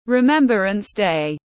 Ngày thương binh liệt sĩ tiếng anh gọi là Remembrance Day, phiên âm tiếng anh đọc là /rɪˈmɛmbrəns deɪ/
Remembrance Day /rɪˈmɛmbrəns deɪ/